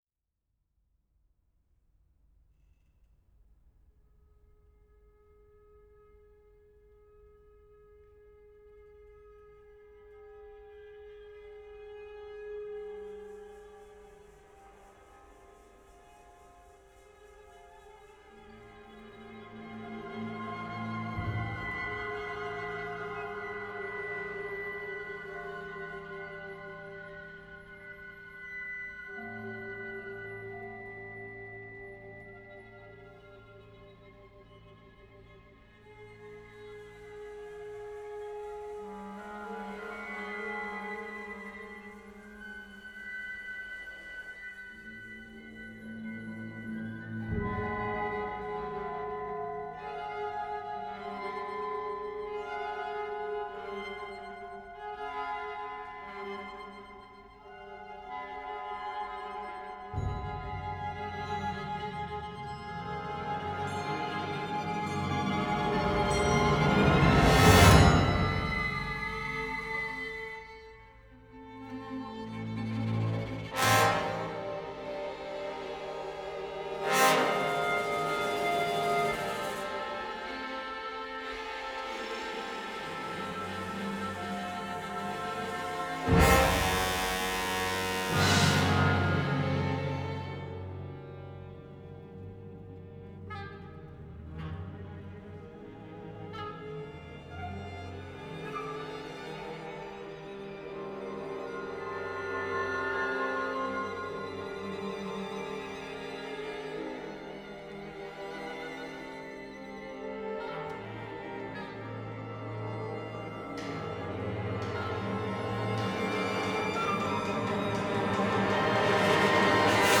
for orchestra